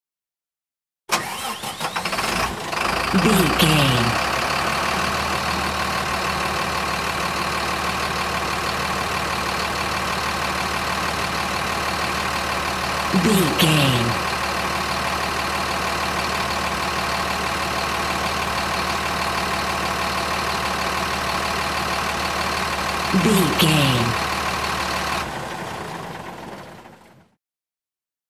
Truck engine start idle
Sound Effects